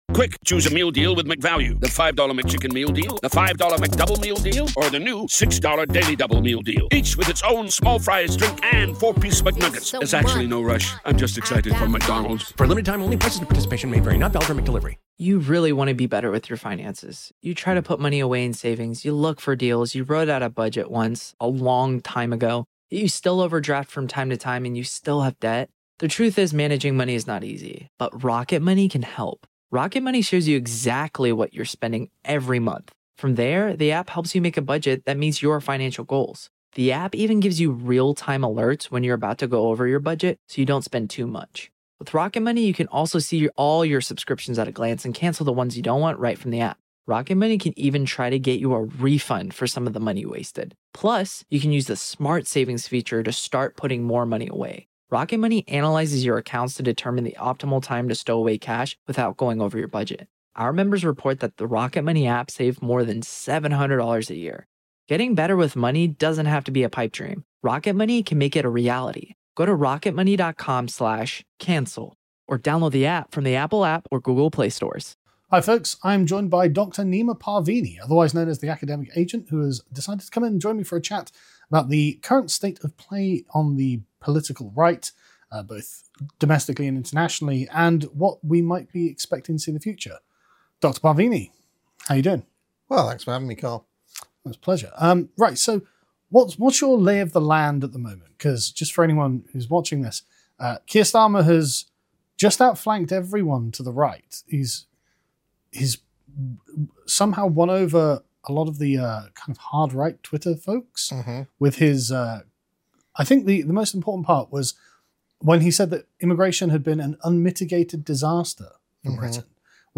PREVIEW: Charting Our Political Future | Interview